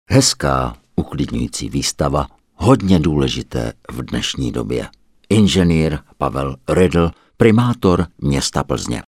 Ing. Pavel Rödl, Primátor města Plzně
U příležitosti konání výstavy na plzeňské radnici – 2009